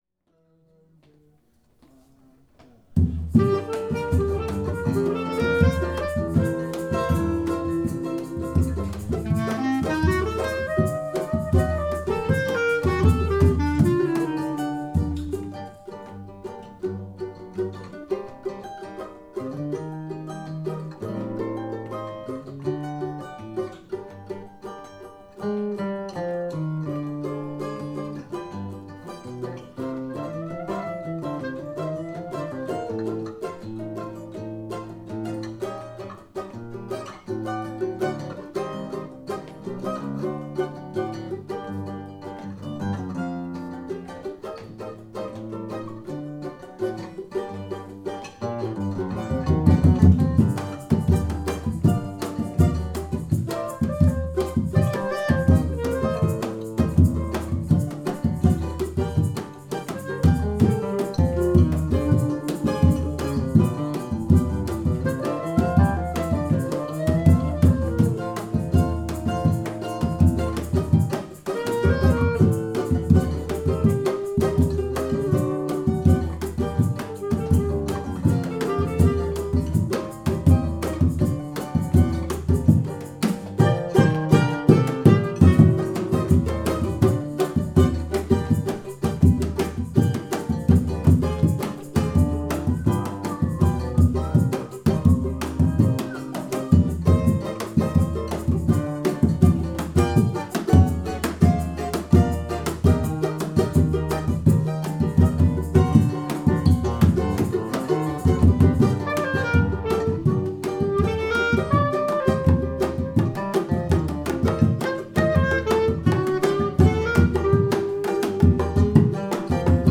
A flor e o espinho instrumental
Rec atelier